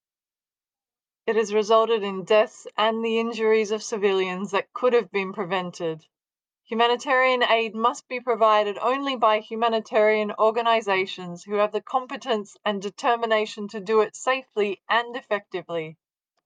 Voicenotes
recorded Monday 2nd June at Nasser Hospital, Gaza